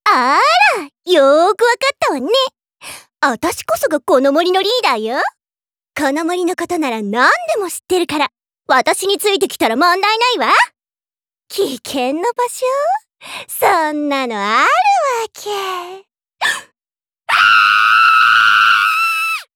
ボイスサンプル3